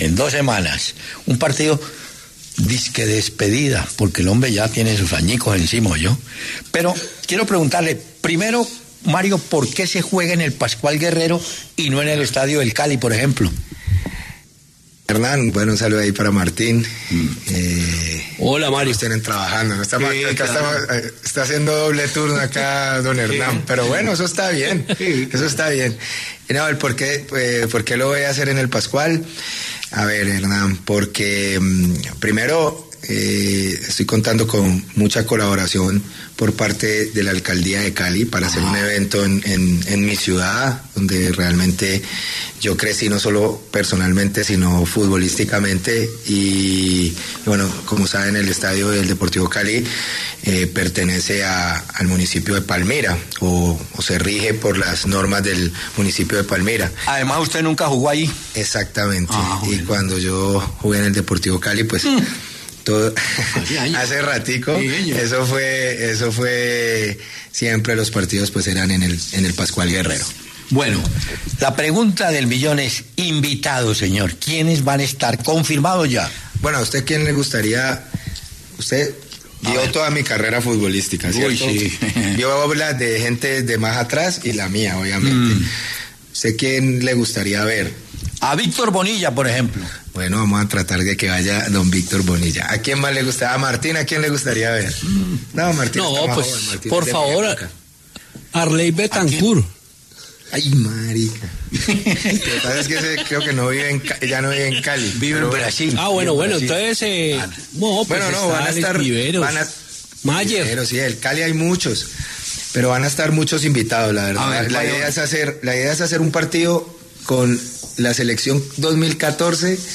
Hernán Peláez y Martín De Francisco hablaron con Mario Alberto Yepes, quien reveló que en su partido de despedida estarán Iván Ramiro Córdoba, Giovanni Hernández, entre otros.
Mario Alberto Yepes, exjugador y excapitán de la Selección Colombia, conversó con Peláez De Francisco en La W acerca de su partido de despedida como futbolista profesional.